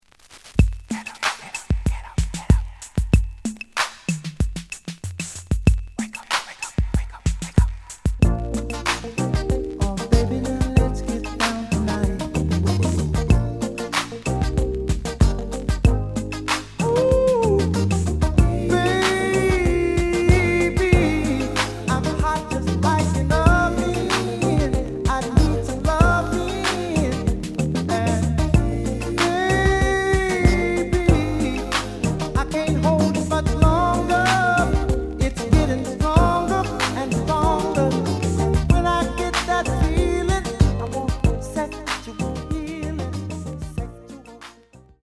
The audio sample is recorded from the actual item.
●Genre: Soul, 80's / 90's Soul
Some noise on beginning of B side.